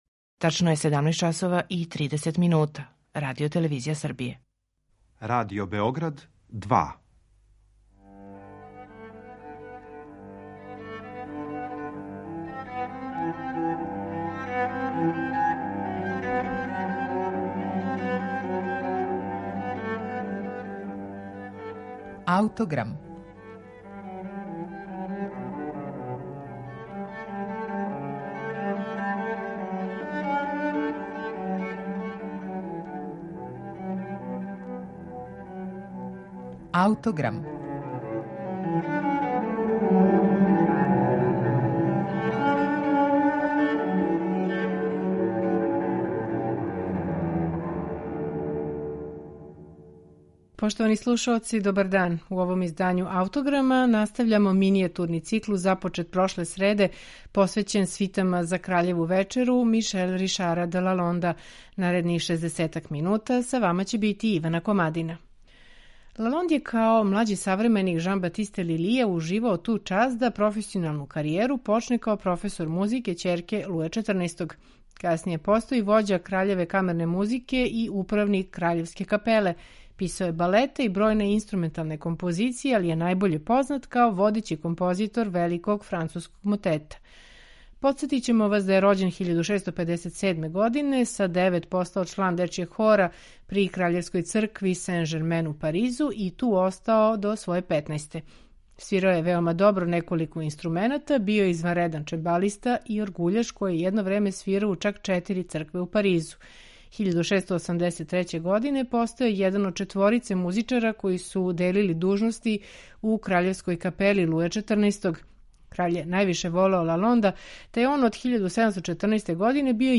Слушаћете 5. и 11. симфонију у интерпретацији чланова ансамбла La Simphonie du Marais